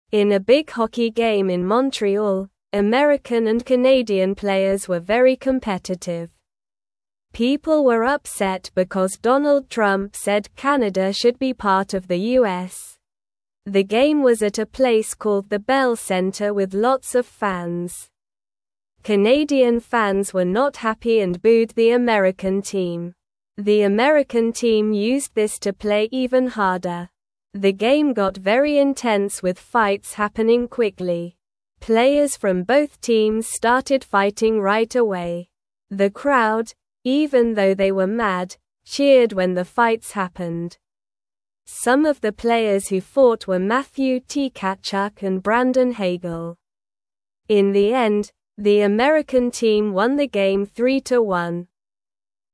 Slow
English-Newsroom-Beginner-SLOW-Reading-Hockey-Game-with-Fights-and-Excited-Fans.mp3